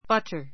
bʌ́tə r バ タ